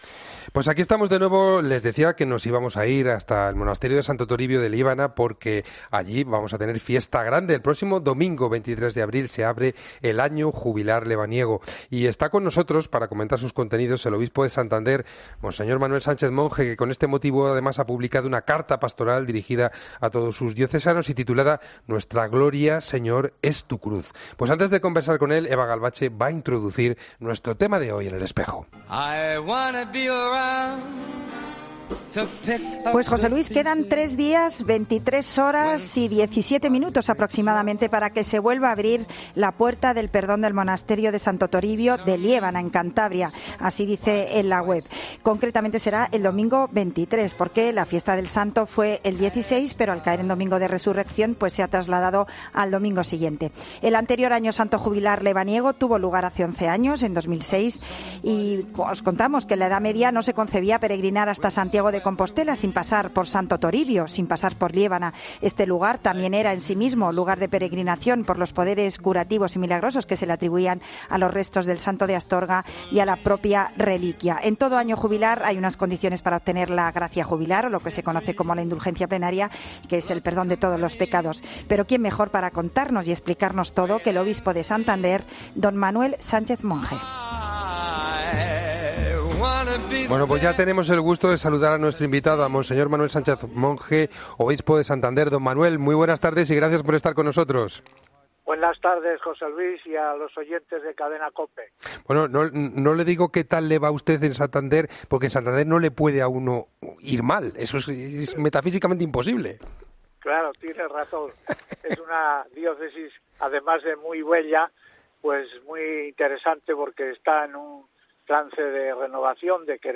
Mons. Sánchez Monge, obispo de Santander, nos presenta el Año Santo Lebaniego